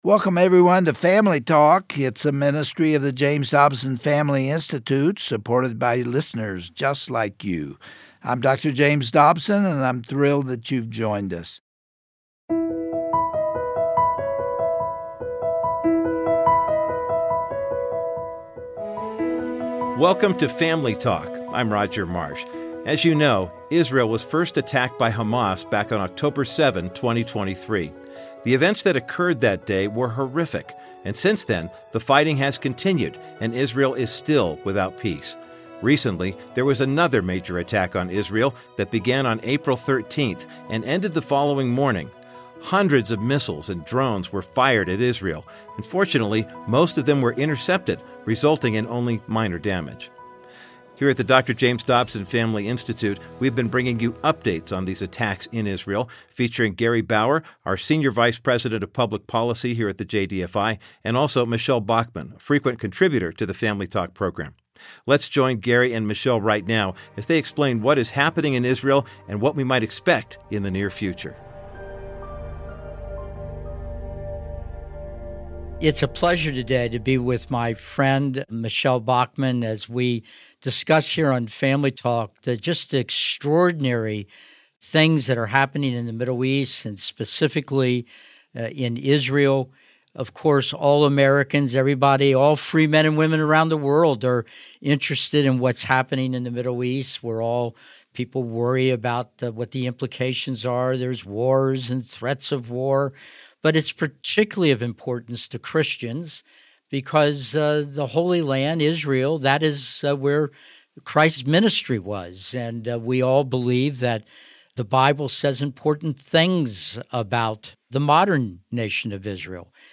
For many years, the nation of Israel has been embroiled in conflict, with the most recent event occurring with hundreds of drones and missiles being shot by Iranian forces into Israeli territory. On today’s edition of Family Talk, co-hosts Gary Bauer and the Hon. Michele Bachmann discuss the latest development in Israel, and what the future could hold.